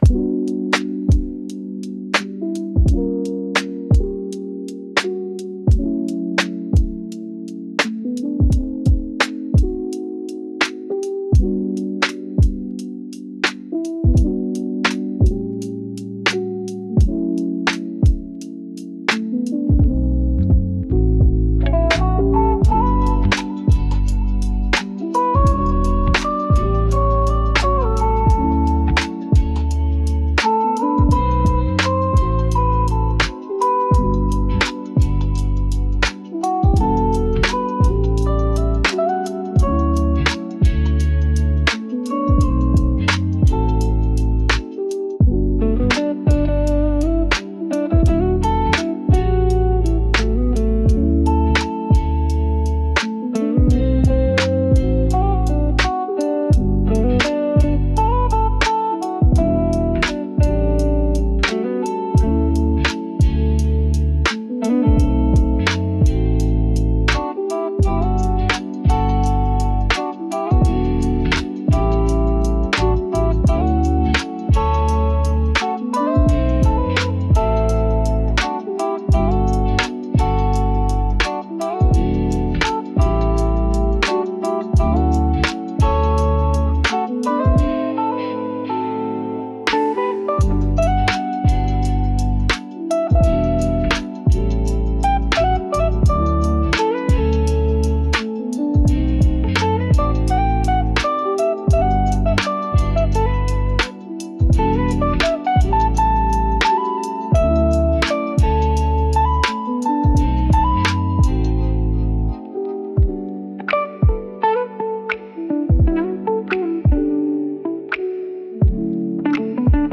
フリーBGM 🎶 夜の都会に流れるような、スムーズでチルなR&BインストBGMです。